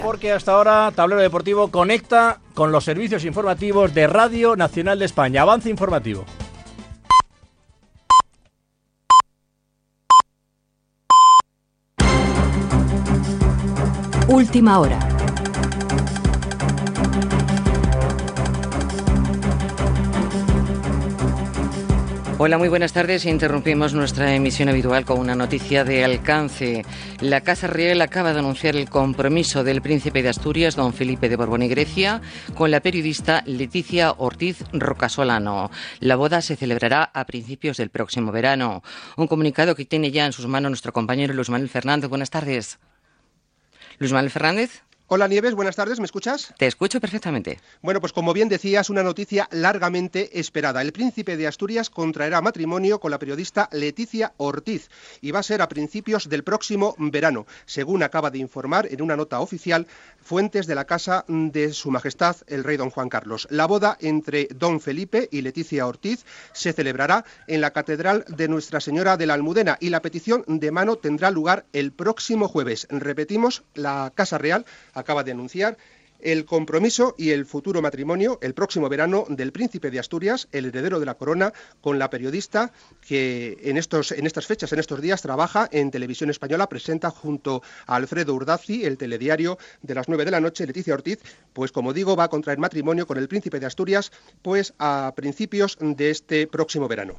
Interrupció de "Tablero deportivo" per fer un avenç informatiu: el prícep d'Astúries Felipe de Borbón es casarà amb la periodista de TVE Leticia Ortiz
Informatiu